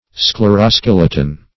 Meaning of scleroskeleton. scleroskeleton synonyms, pronunciation, spelling and more from Free Dictionary.
Search Result for " scleroskeleton" : The Collaborative International Dictionary of English v.0.48: Scleroskeleton \Scle`ro*skel"e*ton\, n. [Gr. sklhro`s hard + E. skeleton.]